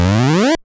その他の効果音
溜めるＡ２段